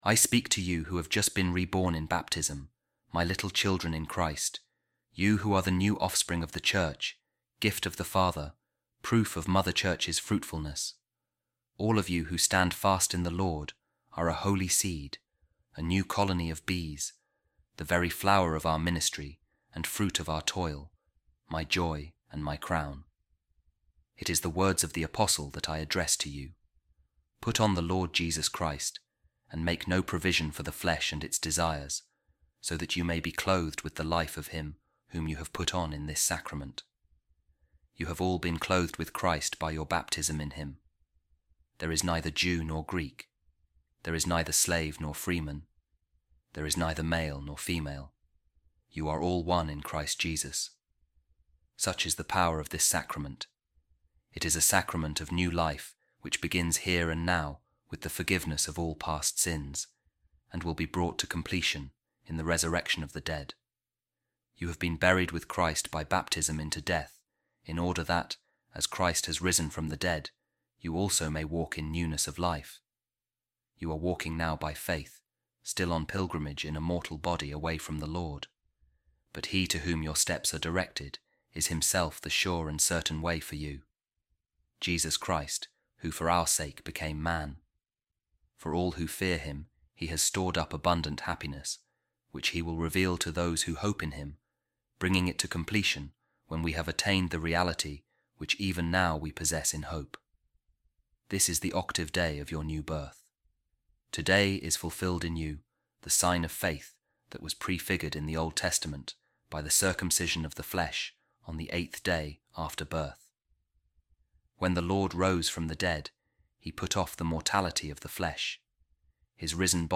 A Reading From The Sermons Of Saint Augustine | A New Creation In Jesus Christ